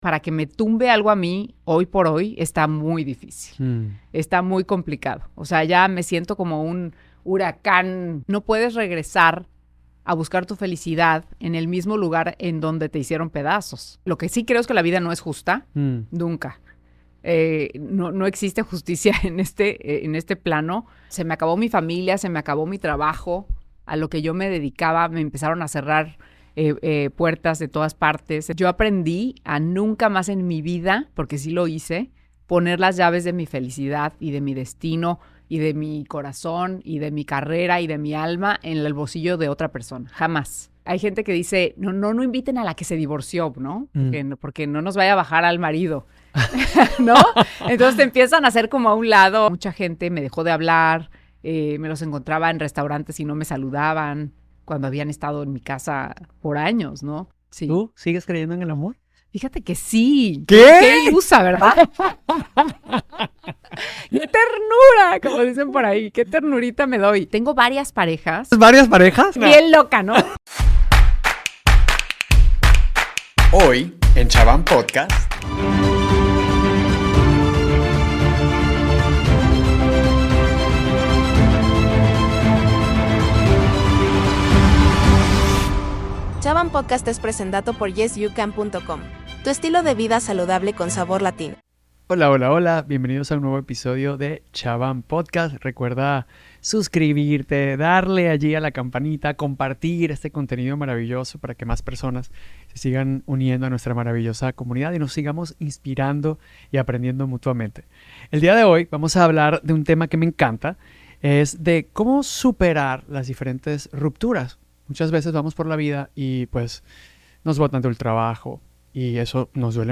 Este episodio trae para ti una conversación sincera y reveladora con una figura destacada de la televisión mexicana, la talentosa Anette Cuburu.